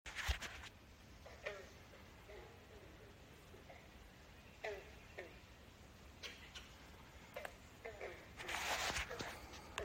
Green Frog